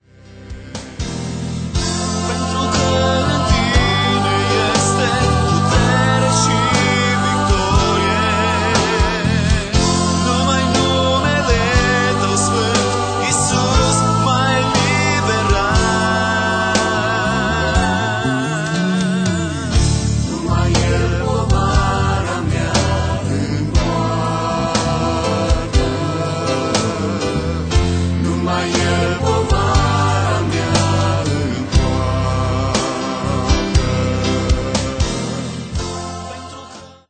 Un alt album de lauda si inchinare